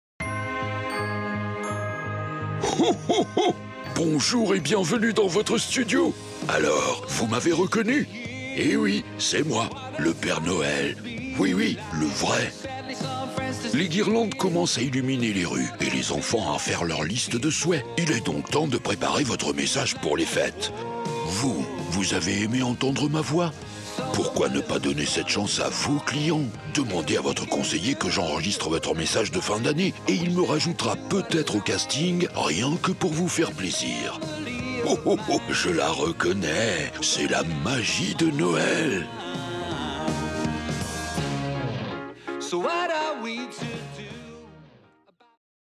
Et si vous utilisiez la voix du Père Noël pour personnaliser votre message !
Écoutez la voix du Père Noël